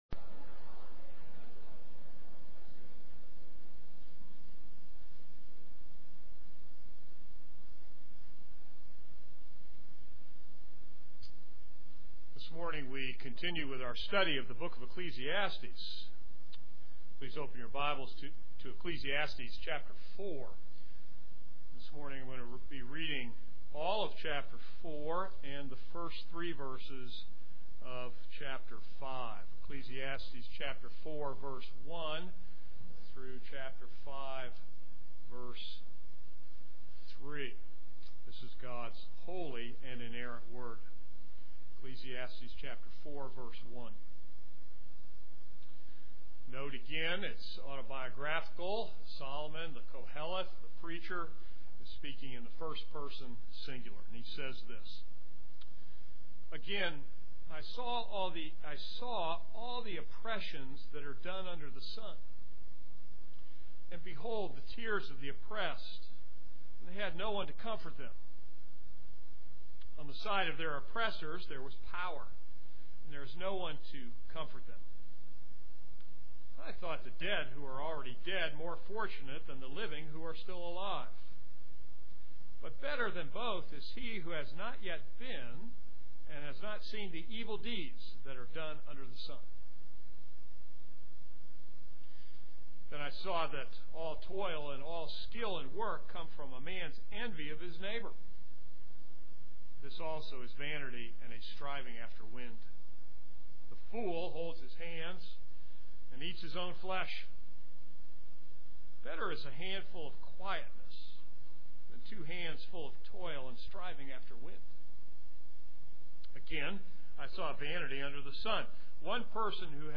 This is a sermon on Ecclesiastes 4:1-5:3.